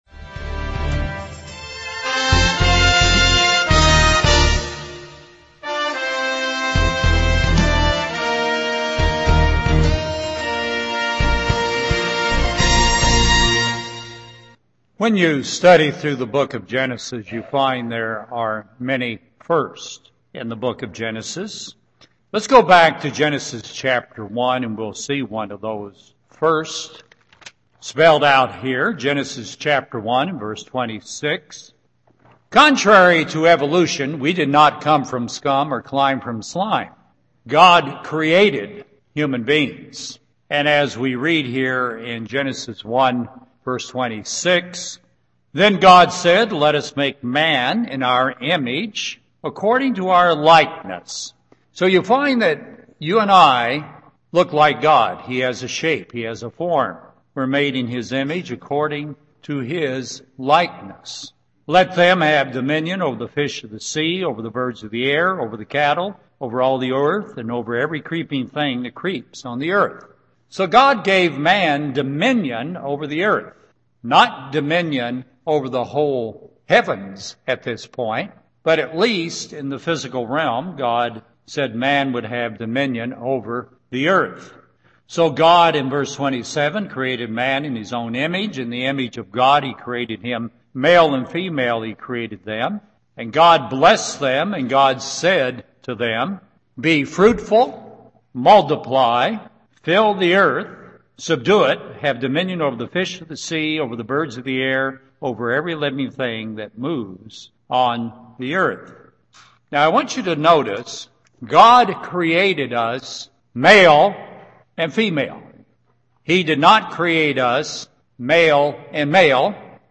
Ephesians 5:1-2 UCG Sermon Transcript This transcript was generated by AI and may contain errors.